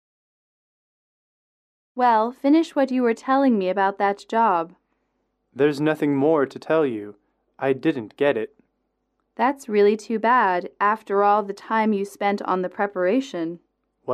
英语主题情景短对话10-2：应聘工作失败（MP3）
英语口语情景短对话10-2：应聘工作失败（MP3）